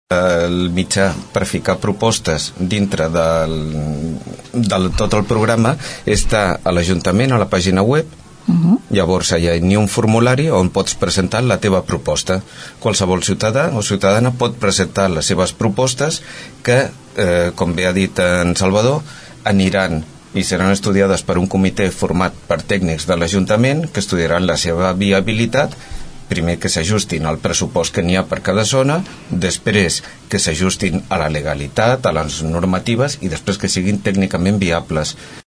El regidor socialista Rafa Delgado, explica les alternatives de participació en cas que no es pugui assistir a les assemblees de zona.